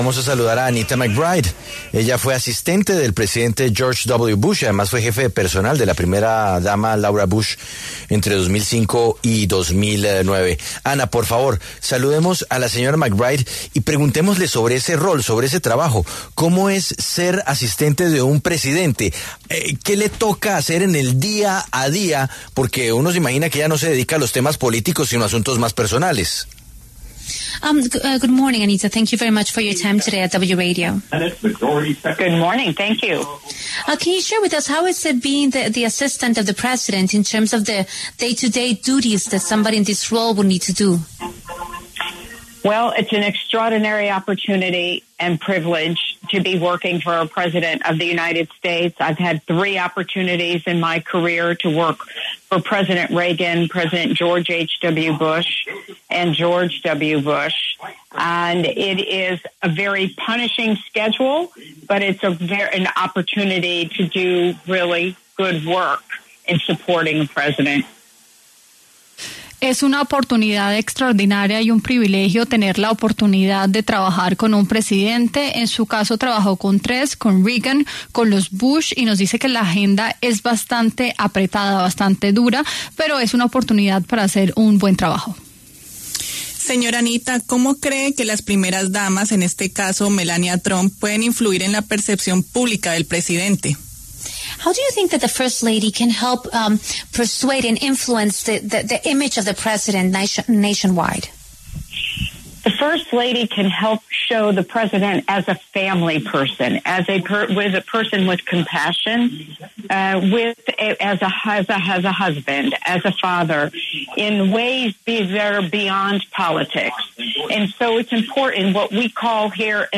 La W conversó con Anita McBride, quien fue asistente de George W Bush, y reveló cómo es trabajar con un presidente de Estados Unidos.